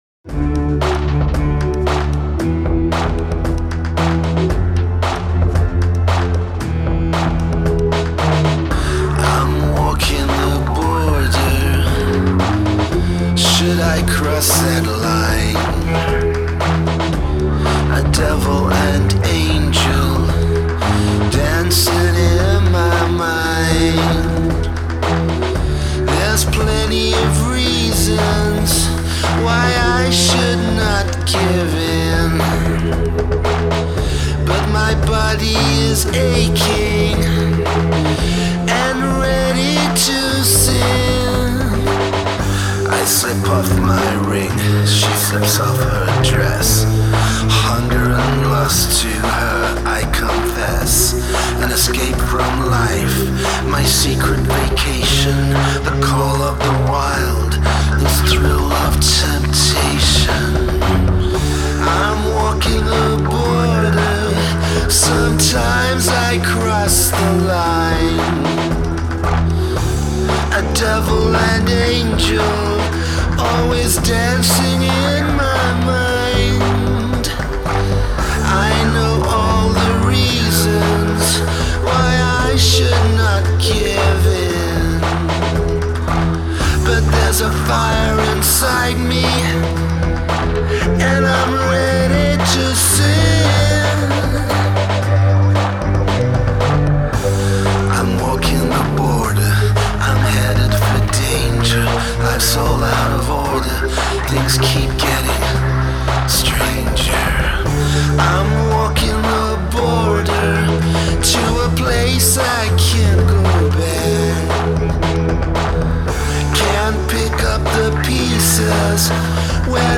Disembodied Male vocalist is strained and gimmicky.
Singer sounds off-key alot of the time.
• Sounds more like Indie/Alternative more than anything.